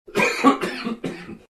cough4.wav